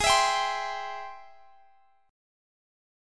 Ab+M7 Chord
Listen to Ab+M7 strummed